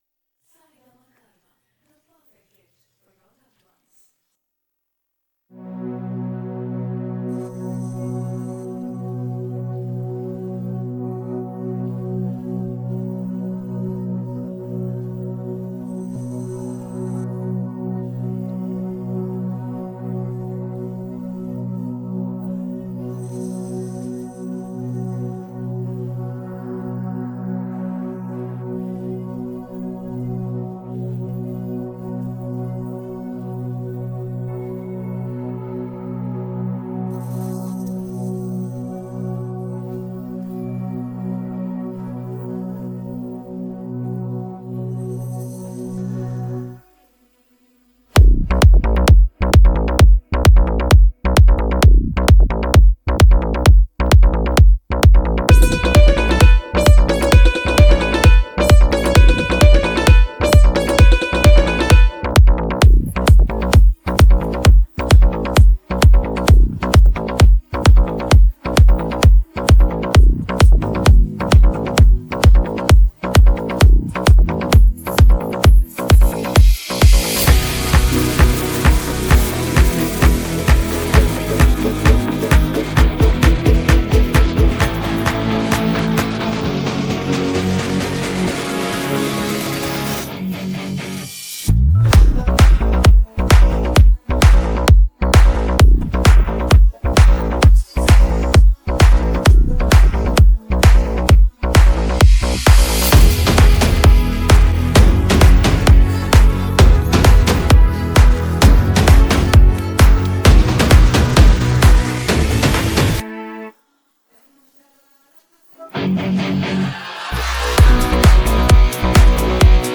fulkHindustani and CarnaticLatin MusicPop